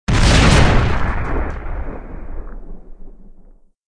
explosion_asteroid_large.wav